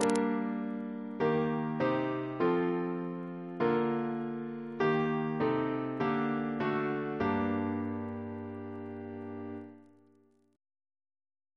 Single chant in G minor Composer: Thomas Kelway (1695-1749) Reference psalters: ACB: 206; OCB: 127; PP/SNCB: 72; RSCM: 190